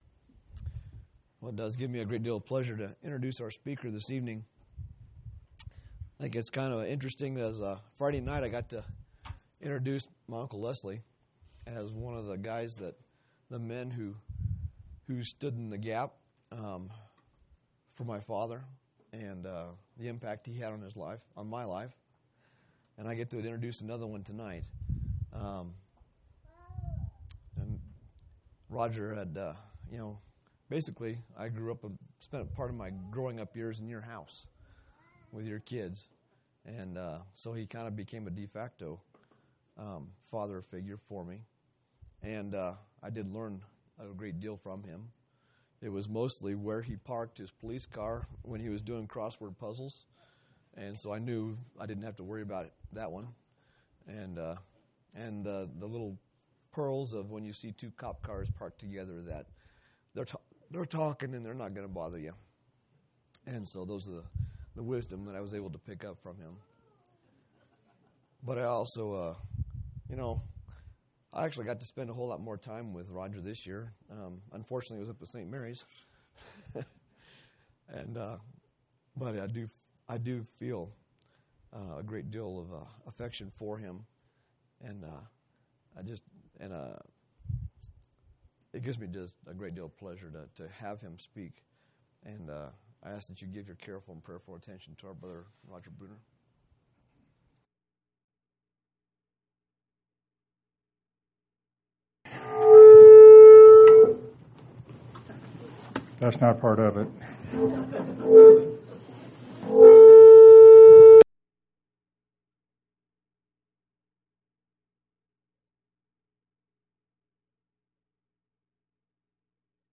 6/10/2018 Location: Colorado Reunion Event